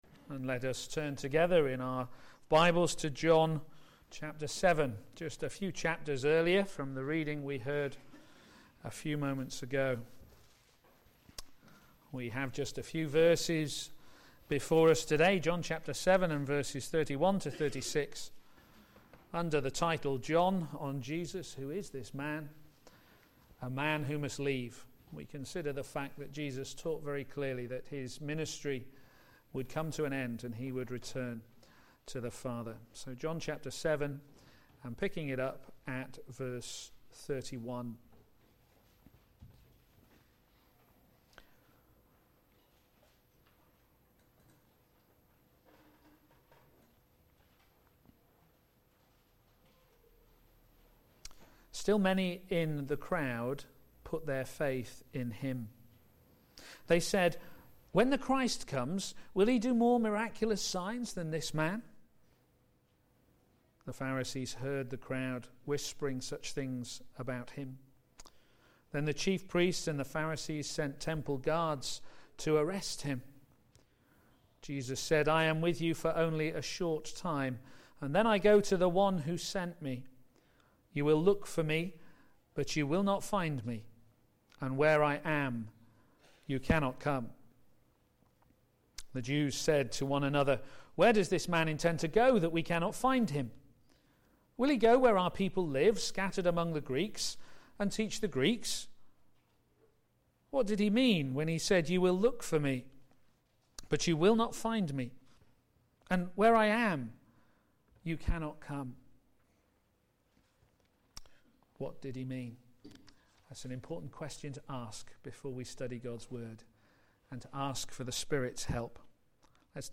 Media for a.m. Service on Sun 28th Apr 2013
Series: John on Jesus Theme: A man who must leave Sermon